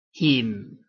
臺灣客語拼音學習網-客語聽讀拼-海陸腔-鼻尾韻
拼音查詢：【海陸腔】him ~請點選不同聲調拼音聽聽看!(例字漢字部分屬參考性質)